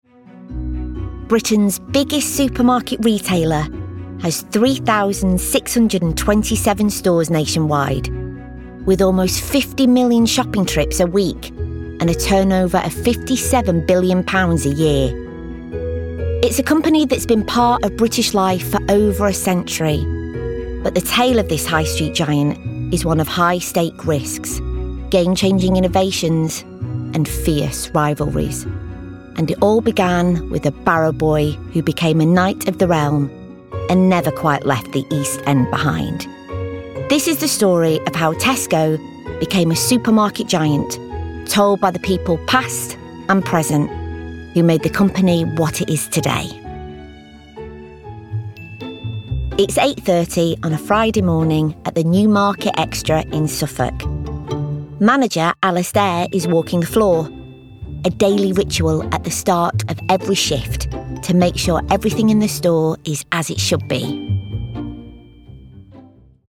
***NEW ARTIST*** | 50s | Warm, Quirky & Natural
Voice reel